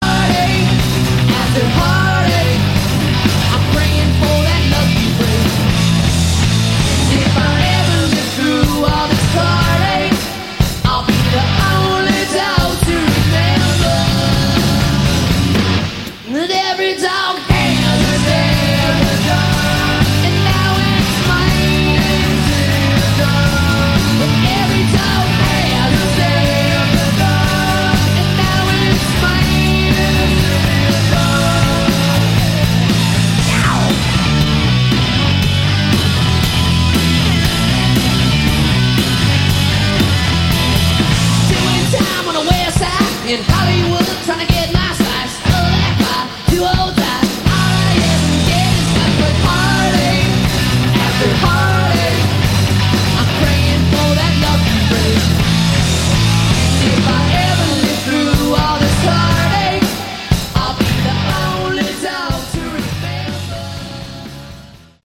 Category: Glam/Hard Rock